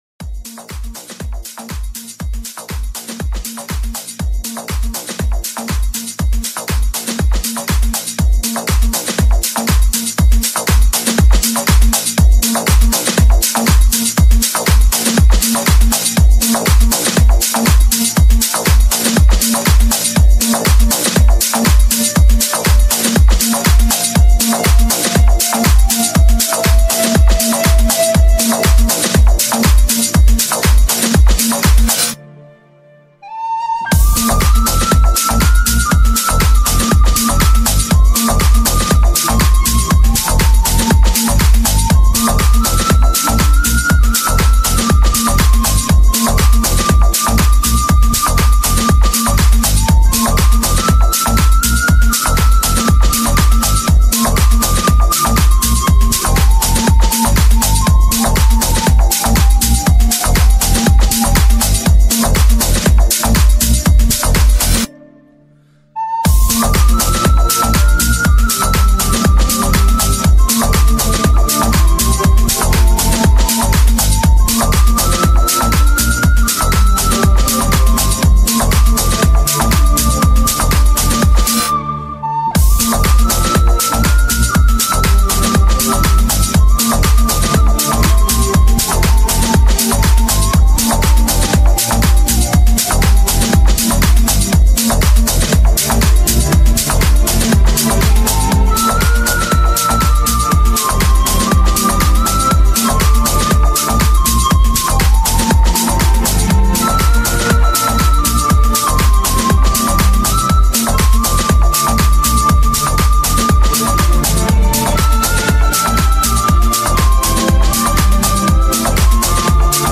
Genre - Tech House
BPM - 120